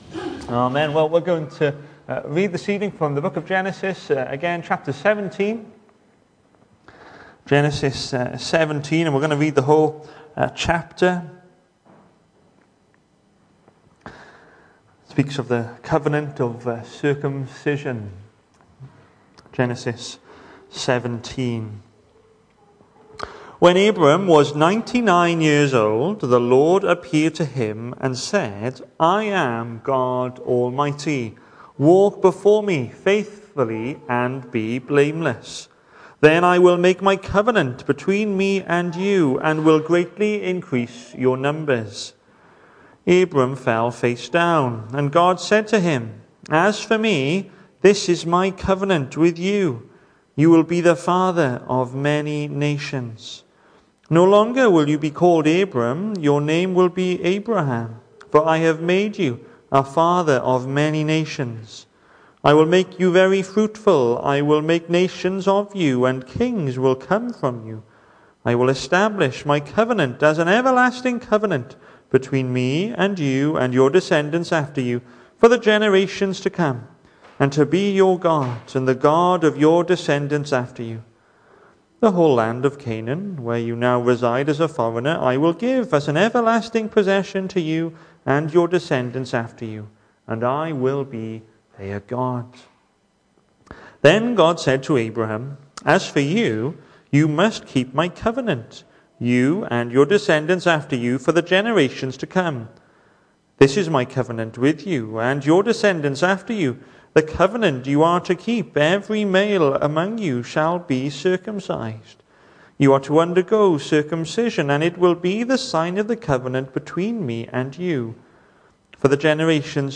Hello and welcome to Bethel Evangelical Church in Gorseinon and thank you for checking out this weeks sermon recordings.
The 26th of October saw us hold our evening service from the building, with a livestream available via Facebook.